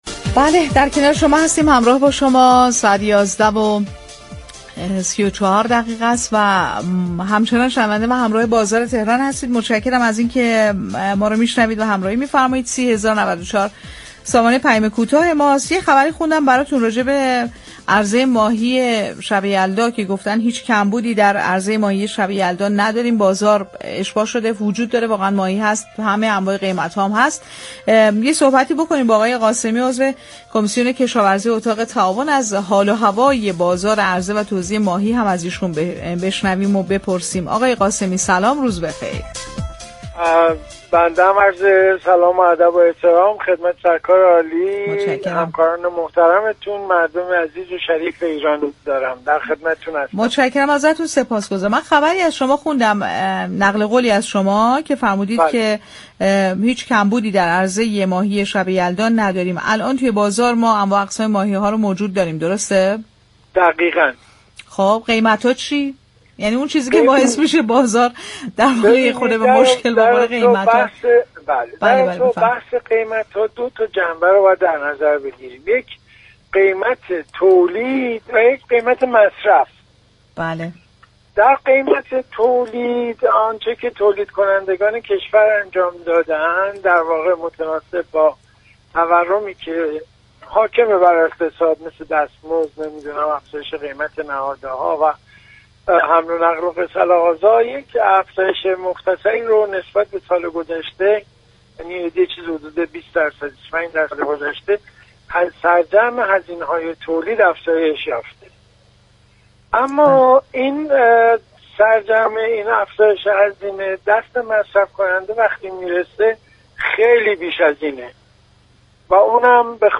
در گفت‌و‌گو با برنامه «بازار تهران»